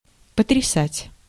Ääntäminen
IPA: /pətrʲɪˈsatʲ/